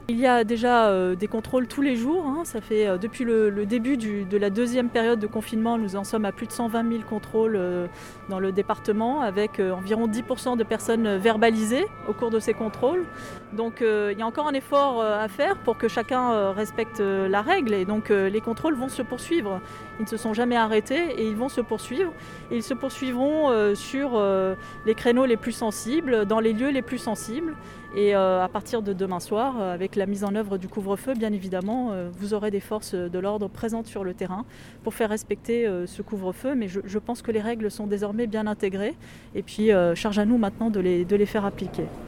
A l’issue d’une cérémonie de prise de fonction dans les formes, avec un dépôt de gerbe au monument des policiers morts du département des Bouches-du-Rhône, un passage en revue des troupes, discours… la nouvelle préfète de police des Bouches du Rhône a rencontré la presse.